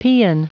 Prononciation du mot paean en anglais (fichier audio)
Prononciation du mot : paean